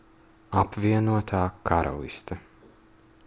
Ääntäminen
United Kingdom US Suku: f .